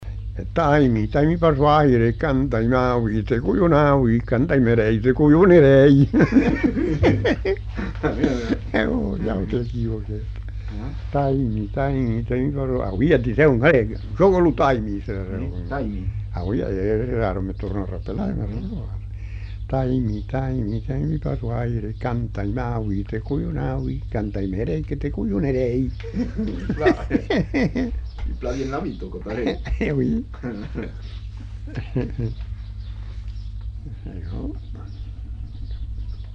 Lieu : Simorre
Genre : chant
Effectif : 1
Type de voix : voix d'homme
Production du son : chanté
Danse : rondeau
Notes consultables : L'interprète répète deux fois le couplet.